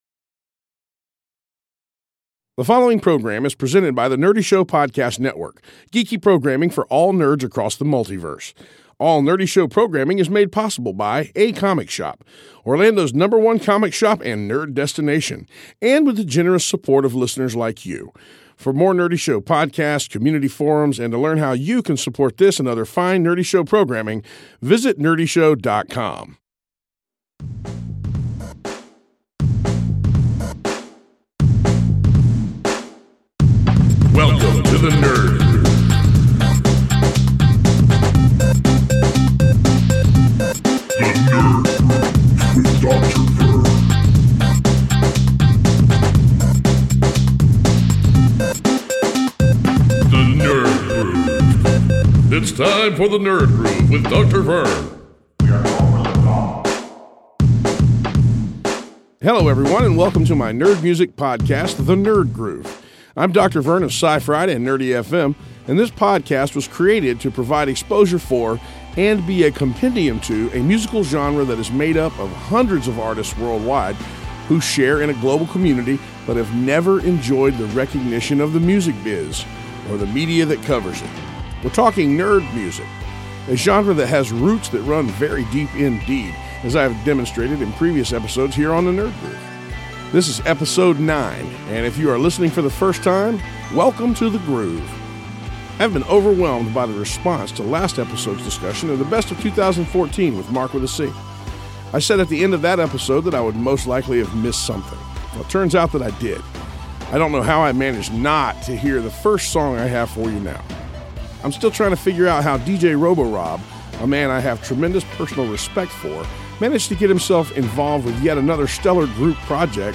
Nerd Music